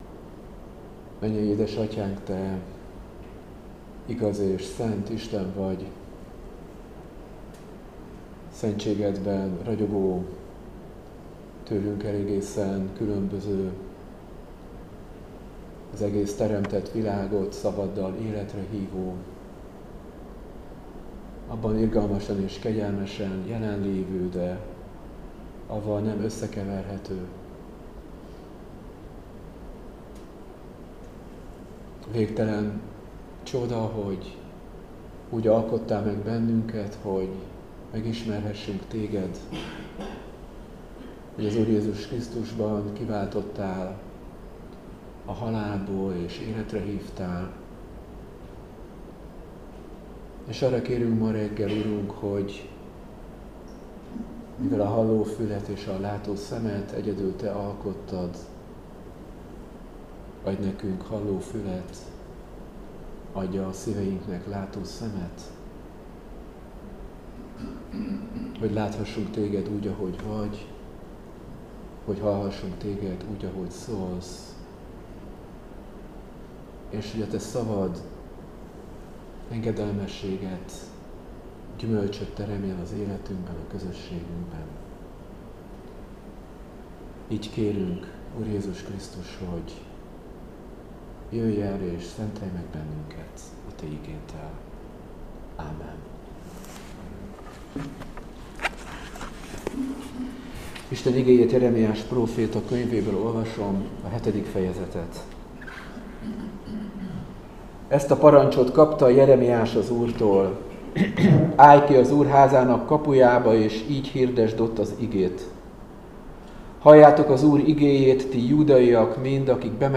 Áhítat, 2026. február 10.